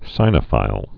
(sīnə-fīl, sĭnə-)